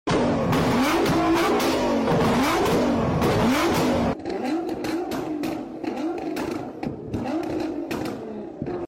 About 🔥 The Nissan R35 GT-R Mp3 Sound Effect
🔥 The Nissan R35 GT-R with Capristo Exhaust is spitting flames and sounding insane!